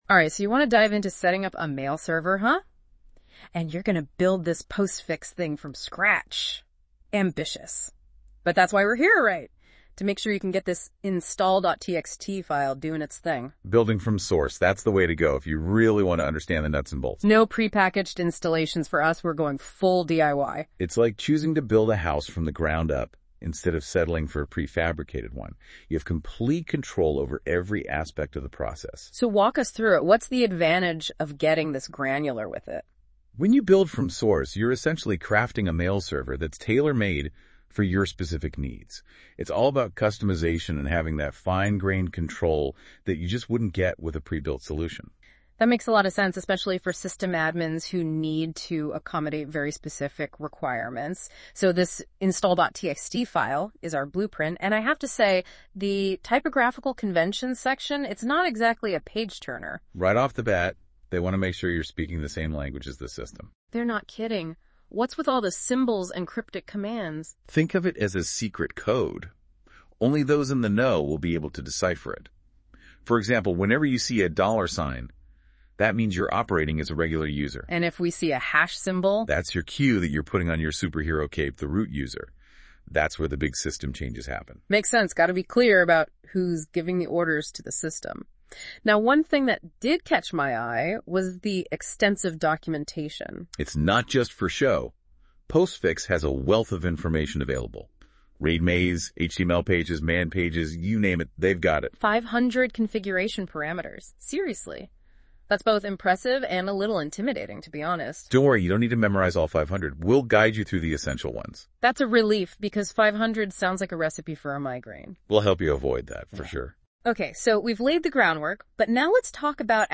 > I then asked it to generate a deep-dive audio podcast conversation
Cute, but I felt it was a bit light on actual content amidst the 7